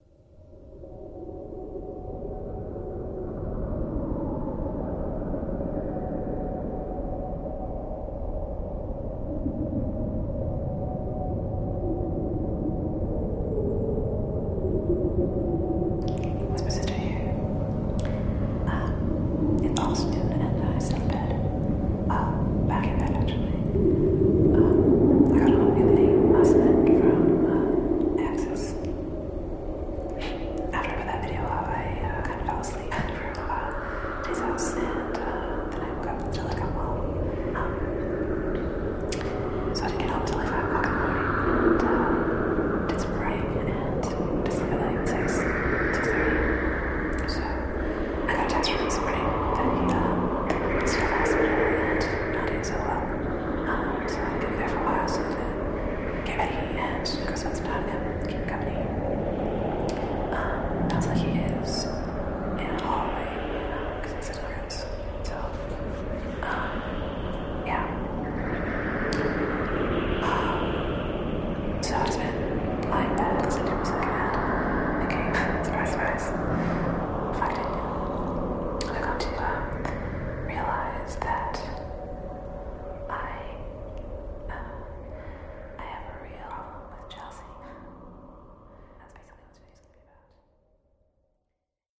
私の抱く「アルベルチーヌ」のイメージに非常に迫る音でありながら、それを更に超越した捉えようのない距離感も同時に備えている。
時に私に慰めを与え、時に挑発的に裏切る「毒」を含んだ緊迫感溢れる音の嵐が始まりも終わりもなく脳を直撃し、身体は熱を帯び浮遊し始める。